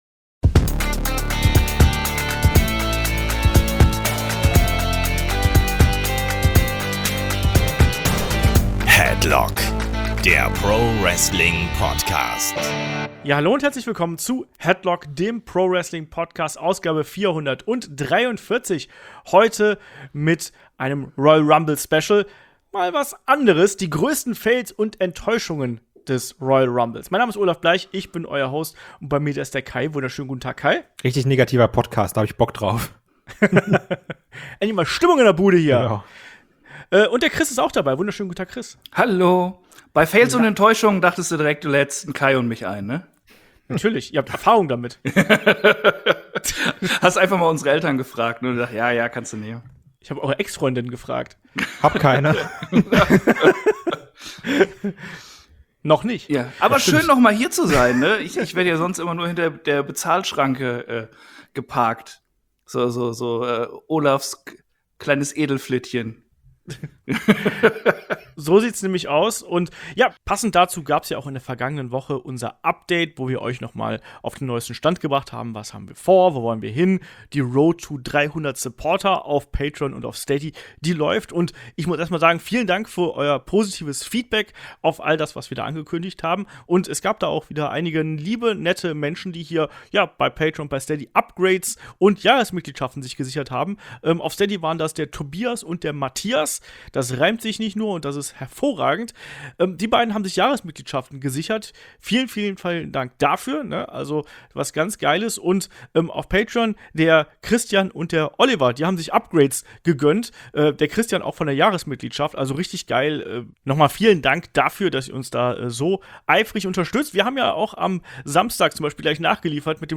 Headlock ist die Wrestling-Gesprächsrunde: Hier plaudert man nicht nur über das aktuelle WWE-Geschehen, sondern wirft auch einen Blick über den Tellerrand.